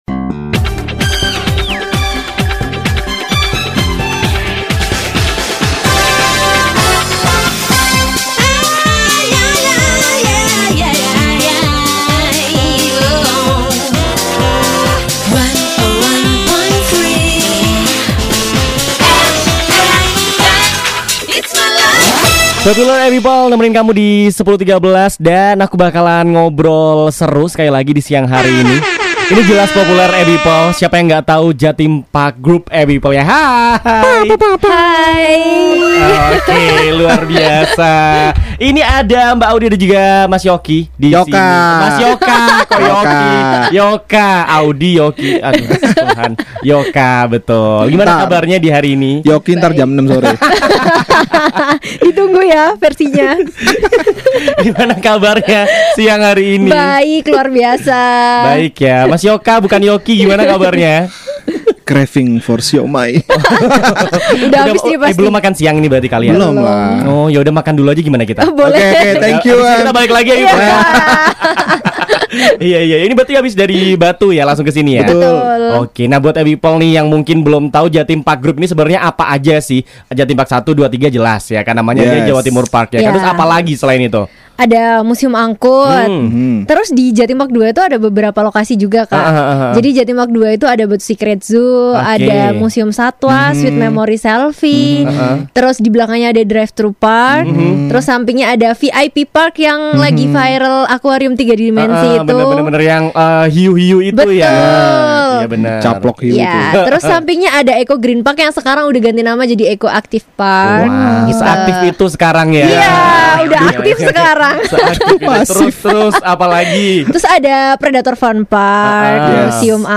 Radio MFM menghadirkan "Ngobrol Seru" bareng Jawa Timur Park tentang apa itu KELIRU (Kejutan Liburan Seru) bersama Jawa Timur Park Group
MFM-TALKSHOW-JTP-GROUP.mp3